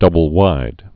(dŭbəl-wīd)